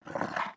npc_wolf_breatherun_05.wav